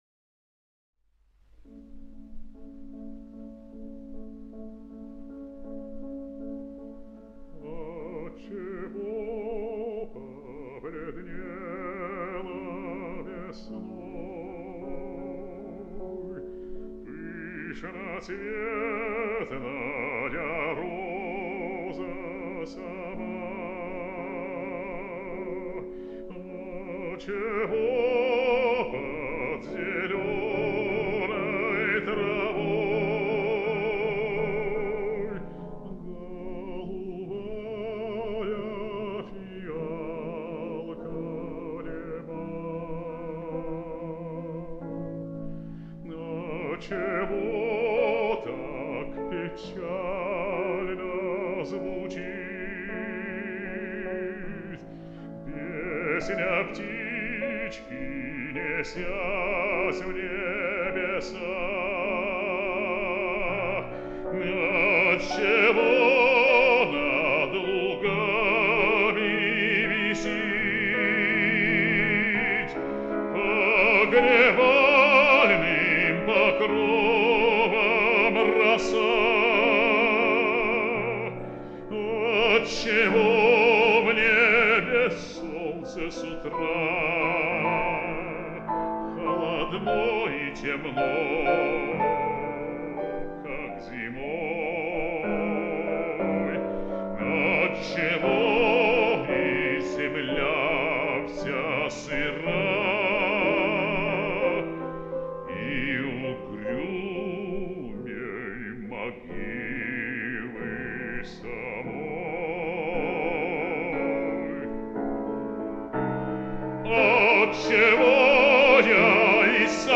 El bajo es un pedazo de bajo, con una fuerza expresiva magnífica.
La veu, l’expressivitat i el sentiment de tendresa manifestat pel baix, m’ha emocionat com cap altre, posant-lo en un llistó molt alt en comparació a la resta.